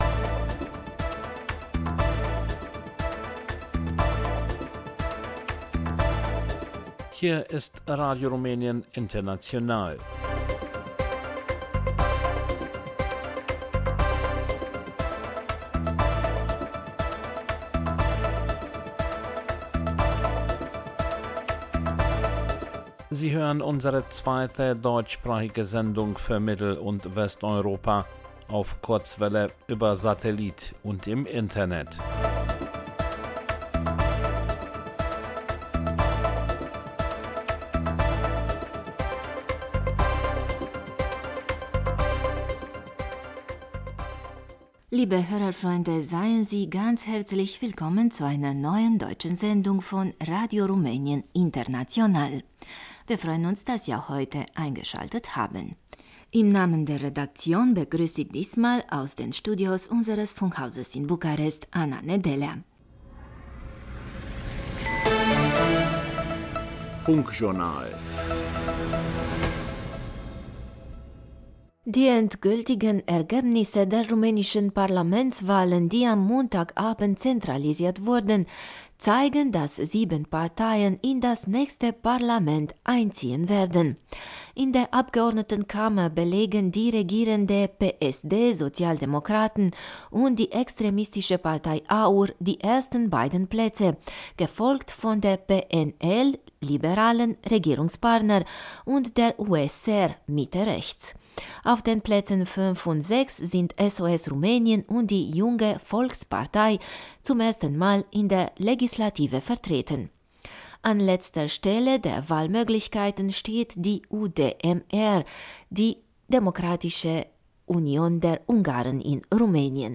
Funkjournal, Enzyklopädie, klassische Musik, Kulturinterferenzen